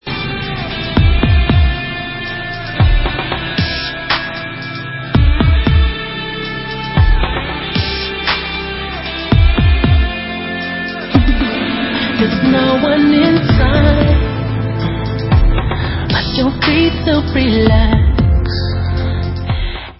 hrající fantastický R&B pop.